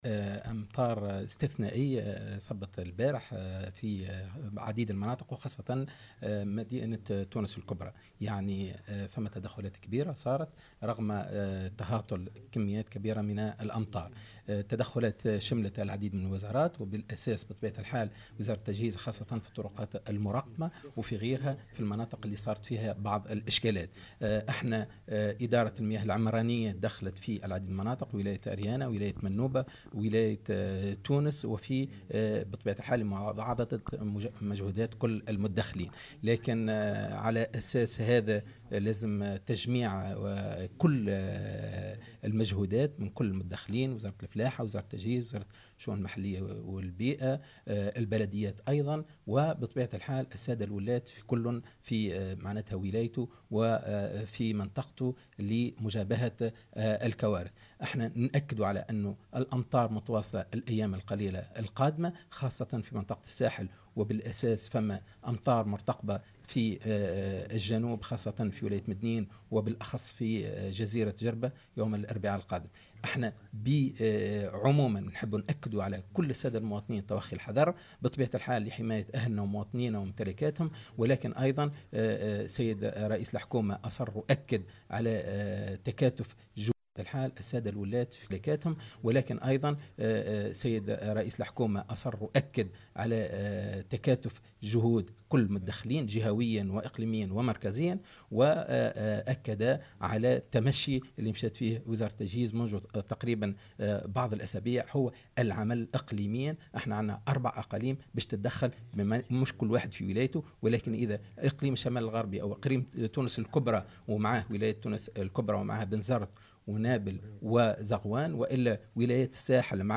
وقال في تصريح عقب جلسة عمل وزارية بقصر الحكومة بالقصبة بإشراف رئيس الحكومة يوسف الشاهد، إن الأمطار الغزيرة متواصلة خلال الأيام القليلة القادمة وخاصة يوم الأربعاء المقبل، داعيا المواطنين إلى توخي الحذر، مشيرا إلى قرار تدخل أجهزة الدولة ومؤسساتها بصفة اقليمية ومن خلال 4 أقاليم تحسّبا لهذه التقلبات المناخية.